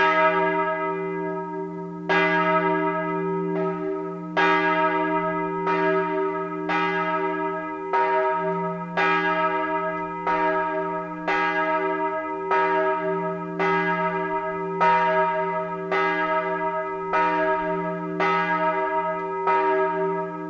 Jakobus-Glocke
Jakobusglocke.mp3